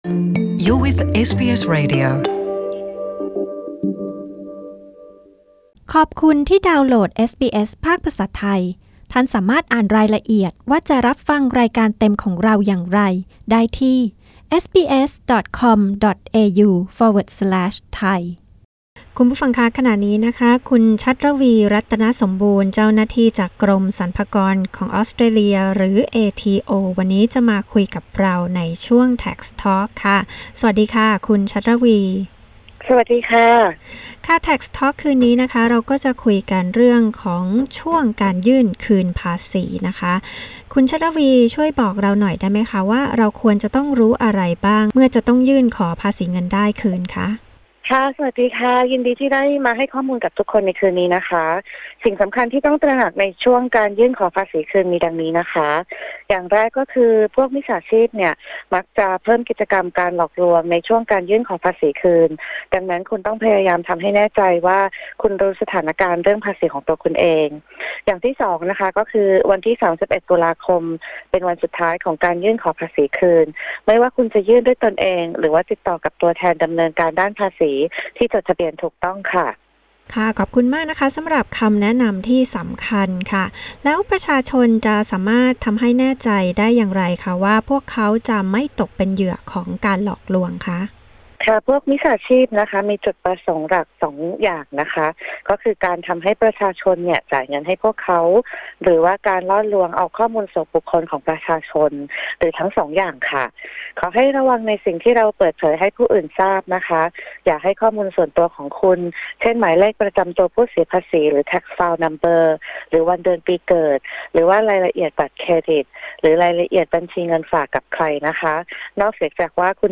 เจ้าหน้าที่จากกรมสรรพากรของออสเตรเลีย ย้ำถึงวันสุดท้ายที่คุณจะยื่นขอคืนภาษีเงินได้สำหรับตัวคุณเอง และสำหรับธุรกิจของคุณได้ พร้อมฝากข้อควรระวังไม่ให้ตกเป็นเหยื่อของการหลอกลวงเกี่ยวกับภาษี